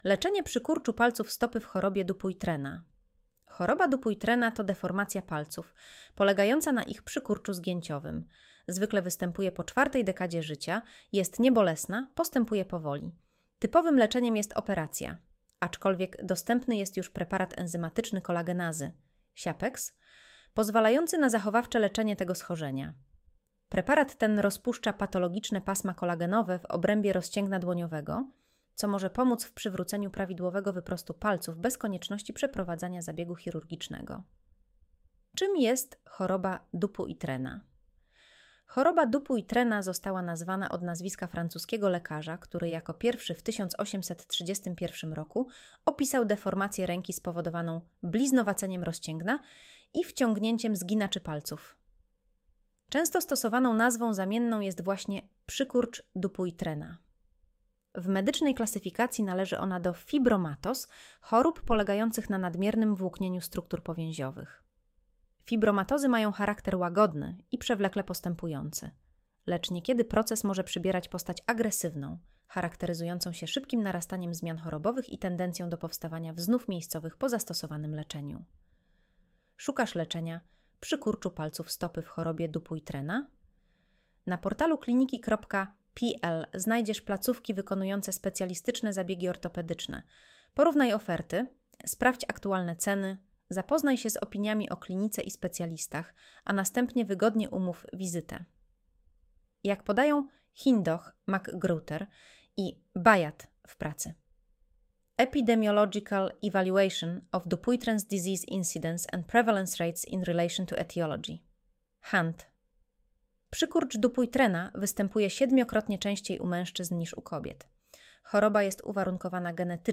Posłuchaj (10:02 min) Streść artykuł Słuchaj artykułu Audio wygenerowane przez AI, może zawierać błędy 00:00 / 0:00 Streszczenie artykułu (AI): Streszczenie wygenerowane przez AI, może zawierać błędy Spis treści Czym jest choroba Dupuytrena?